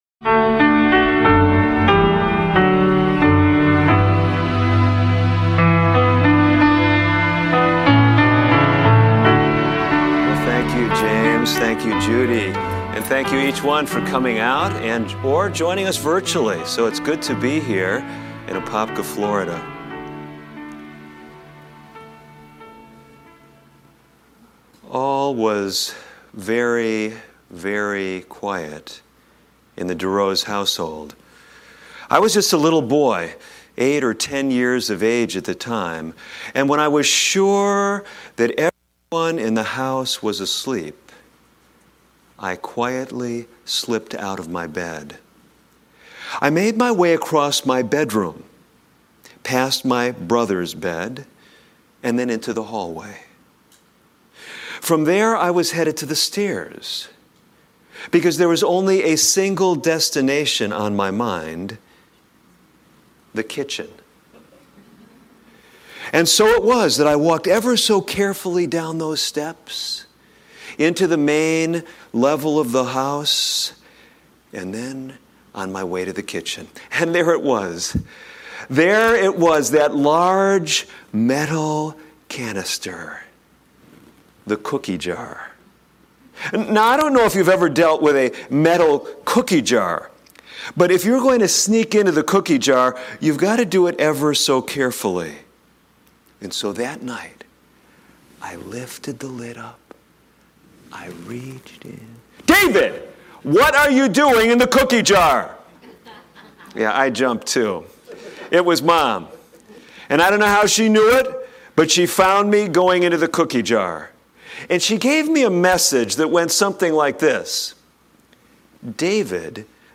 Discover how faith, food, and habits intertwine to shape a fulfilling, healthy life in this insightful talk.